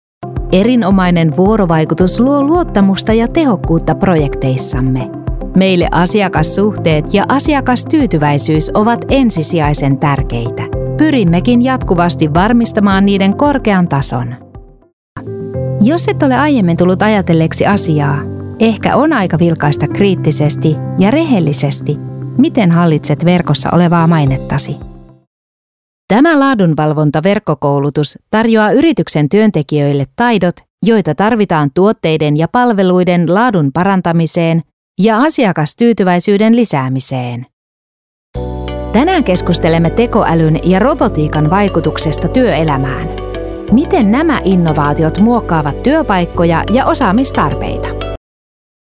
Finnish Voice Talent